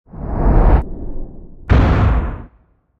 explode.ogg.mp3